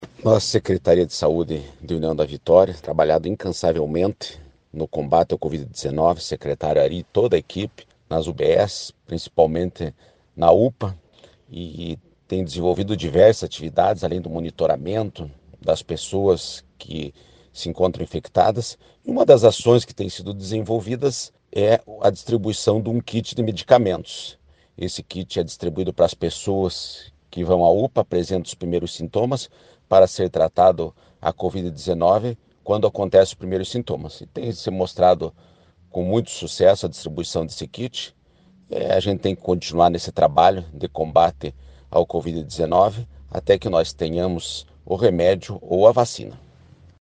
O vice-prefeito Bachir Abbas falou da importância do Kit Vida que vem apresentando um efeito positivo.
Bachir-sobre-medicamentos.mp3